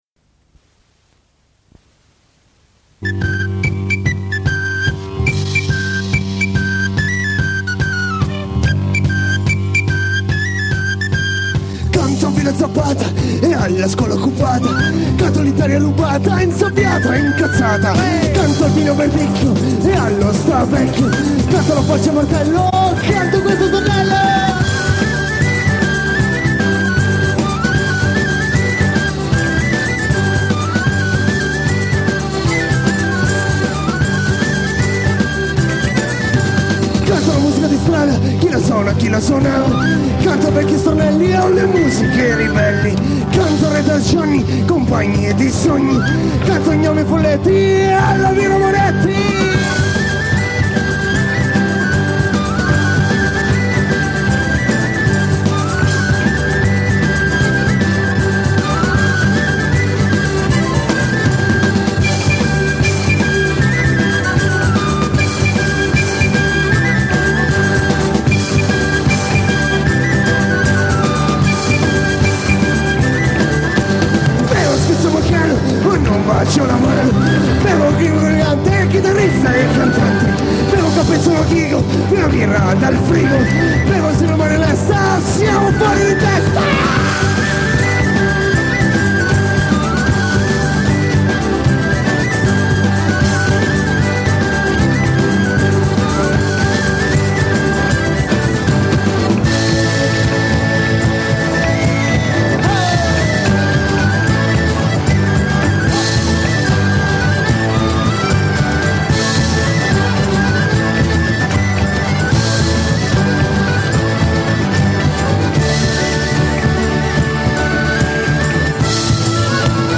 LIVE in Senigallia 1998